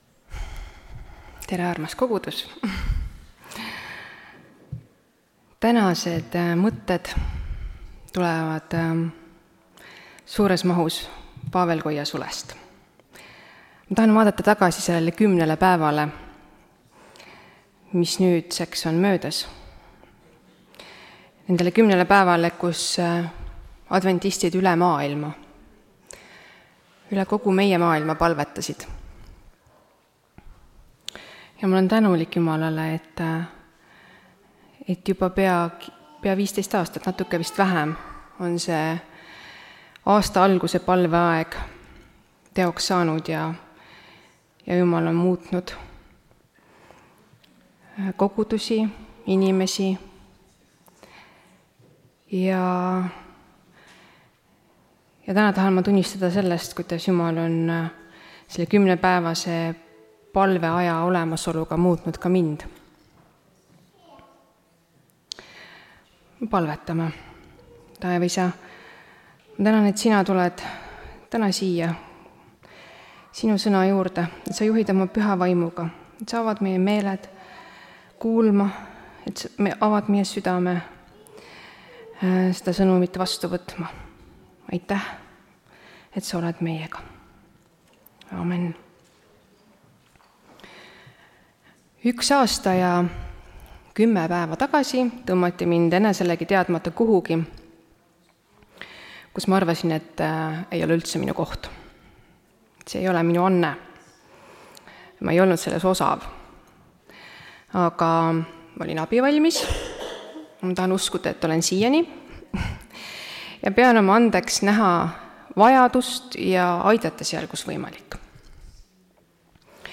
Tartu adventkoguduse 18.01.2025 hommikuse teenistuse jutluse helisalvestis.